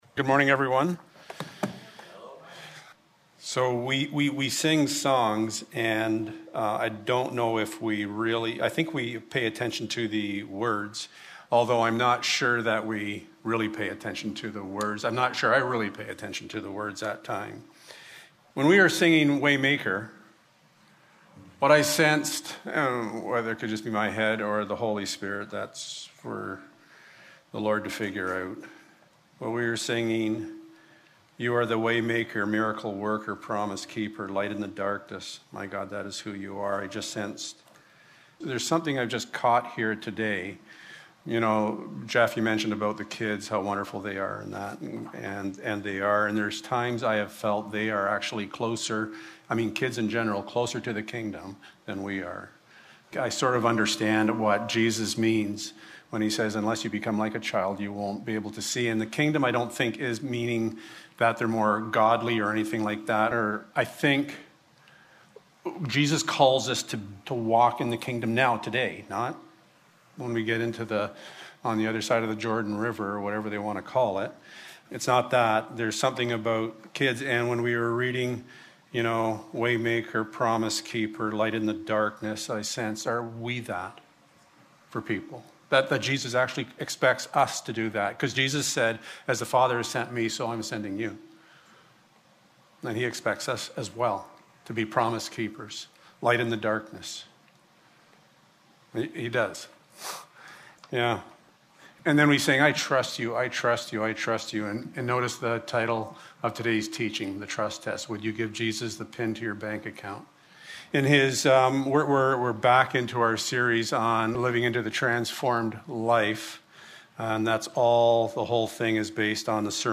Matthew 6:24 Service Type: Sunday Morning Would You Give Jesus the PIN to Your Bank Account?